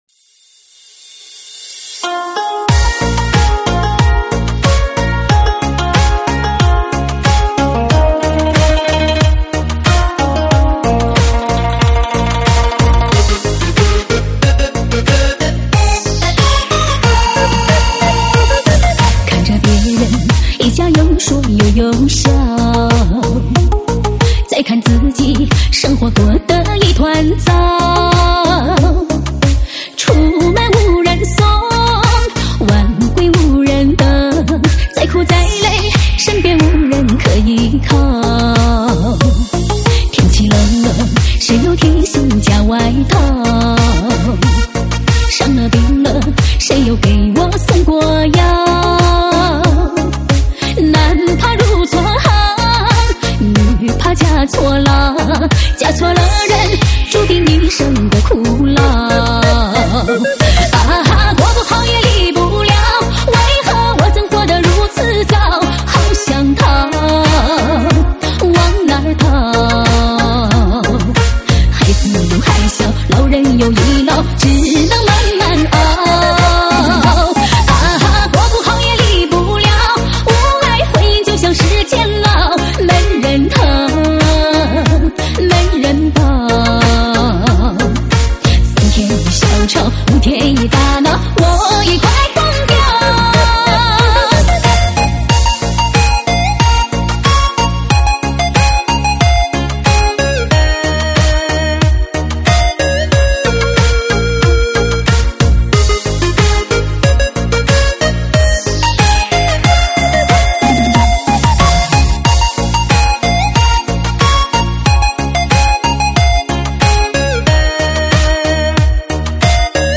舞曲类别：车载大碟